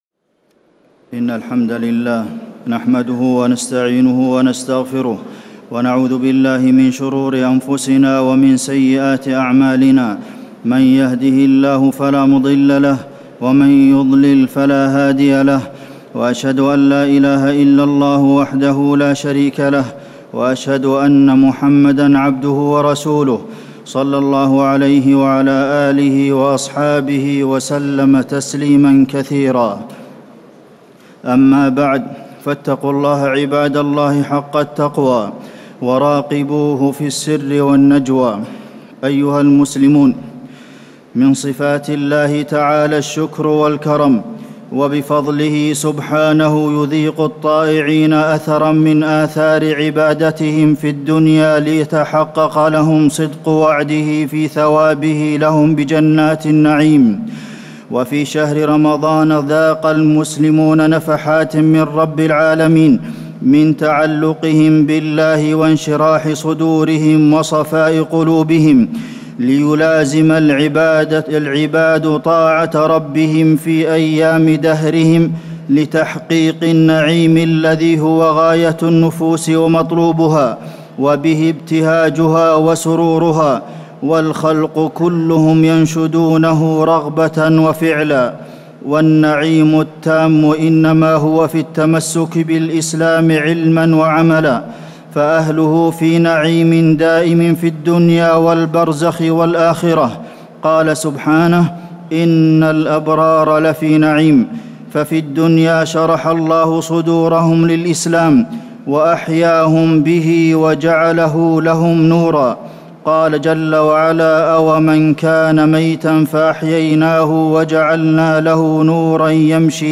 خطبة عيد الفطر- المدينة - الشيخ عبدالمحسن القاسم - الموقع الرسمي لرئاسة الشؤون الدينية بالمسجد النبوي والمسجد الحرام
تاريخ النشر ١ شوال ١٤٣٩ هـ المكان: المسجد النبوي الشيخ: فضيلة الشيخ د. عبدالمحسن بن محمد القاسم فضيلة الشيخ د. عبدالمحسن بن محمد القاسم خطبة عيد الفطر- المدينة - الشيخ عبدالمحسن القاسم